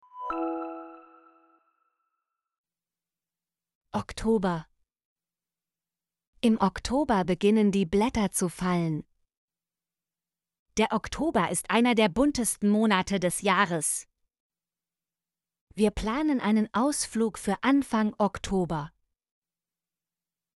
oktober - Example Sentences & Pronunciation, German Frequency List